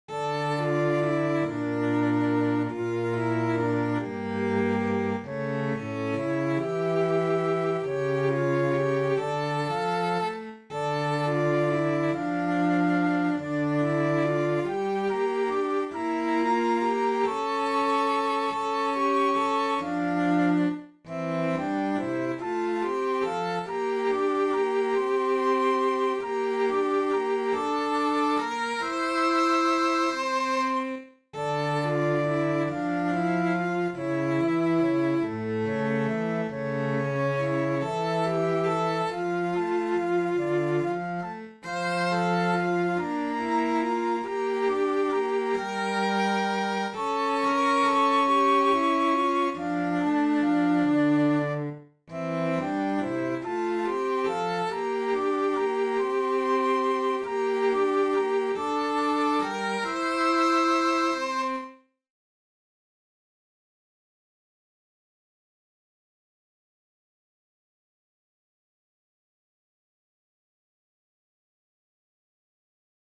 Aurinko-on-joka-saalla-sellot-ja-huilu.mp3